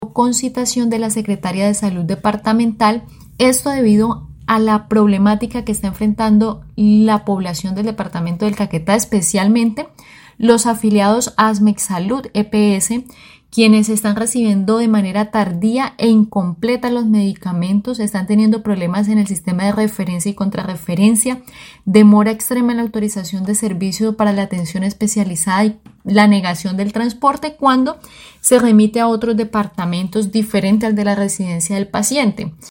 Viviana Silva, diputada liberal, dijo que la citada EPS viene generando una serie de afectaciones a sus afiliados como la no entrega de medicamentos, la demora en la atención y la negación del transporte cuando el paciente es remitido a otra ciudad del país.
DIPUTADA_VIVIANA_SILVA_SALUD_-_copia.mp3